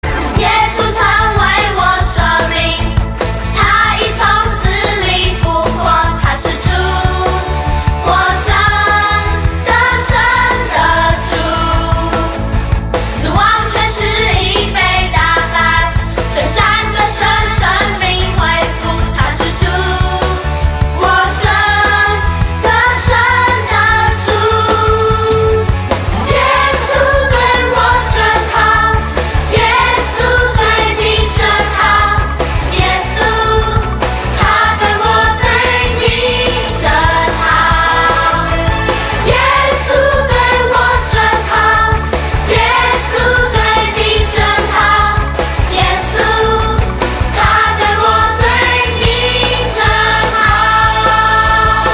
全部商品 / 音樂專區 / 中文專輯 / 兒童敬拜
12首充滿活力與感動的敬拜讚美+ 7首傳遞堅定愛神的精彩MV
聽見孩子真摯的歌聲 可以改變您的心情  看見神所創造的美好